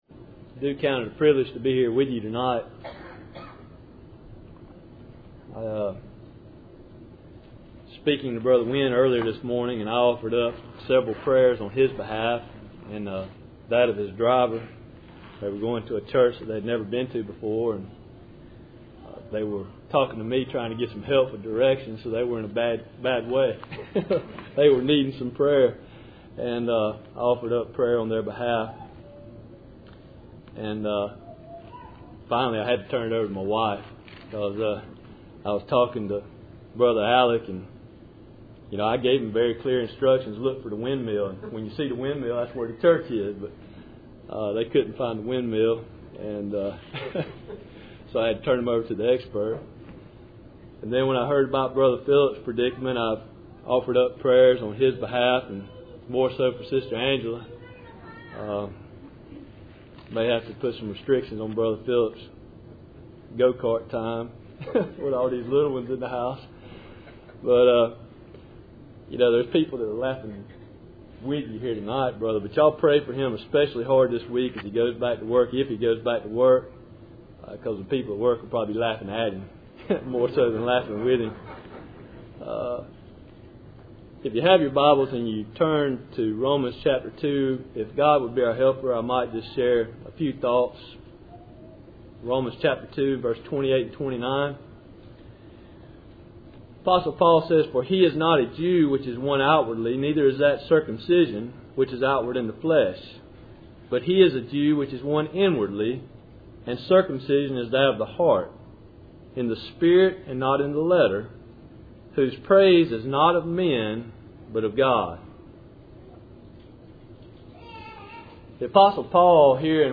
Cool Springs PBC Sunday Evening %todo_render% « Faithful Saying